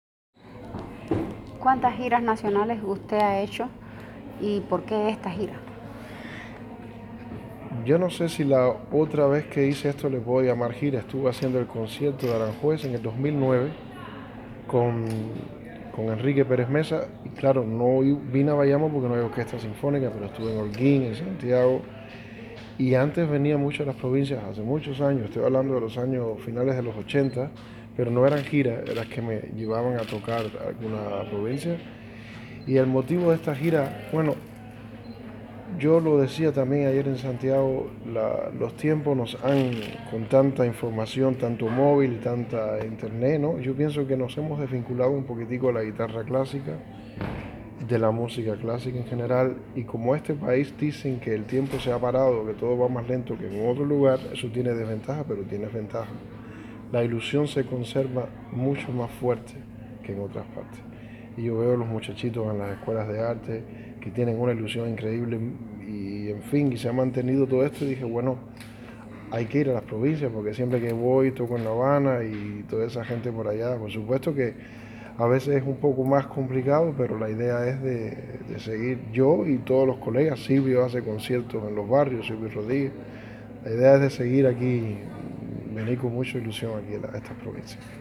guitarrista